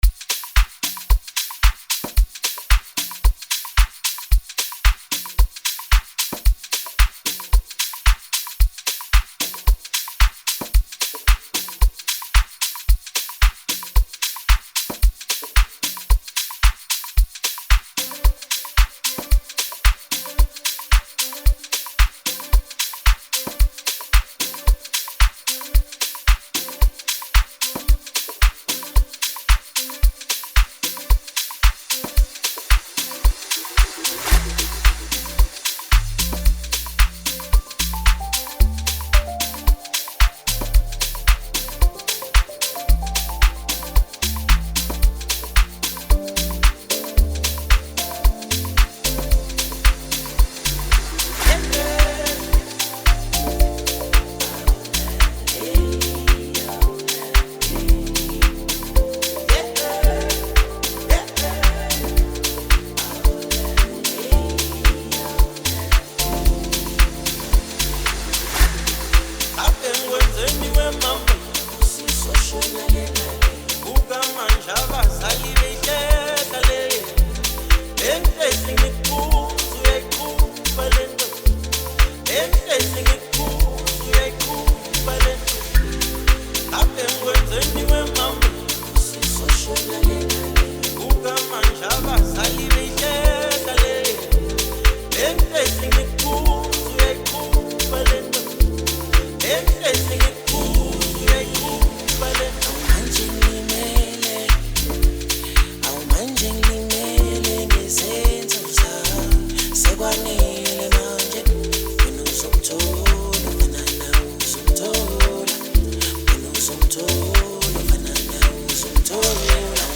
smooth vocals
blends Afrobeat with contemporary sounds
With its high energy tempo and catchy sounds